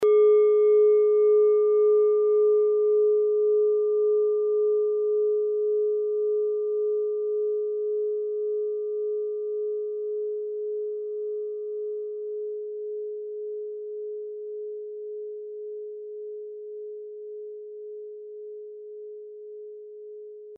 Klangschale Nepal Nr.11
(Ermittelt mit dem Filzklöppel)
Der Neptunton liegt bei 211,44 Hz, das ist nahe beim "Gis".
klangschale-nepal-11.mp3